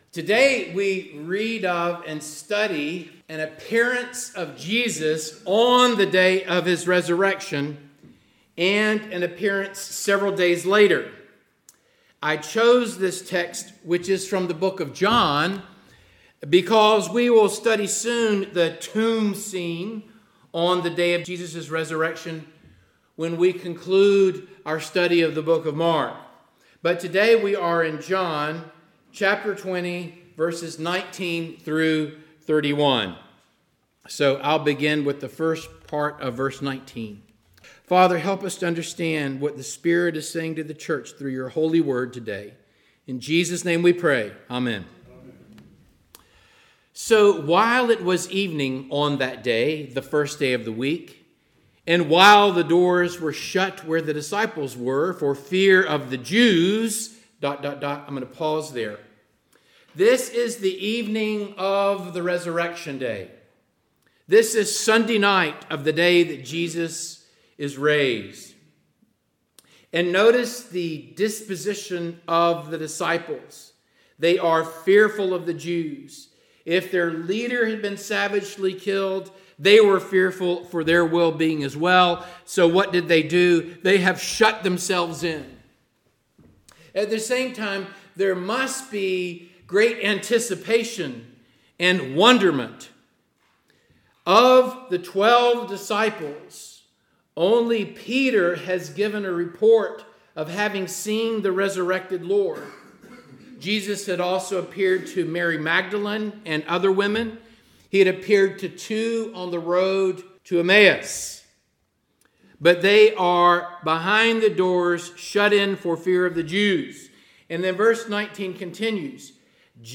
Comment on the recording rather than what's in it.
Passage: John 20:19-31 Service Type: Morning Service Download Files Bulletin « Good Friday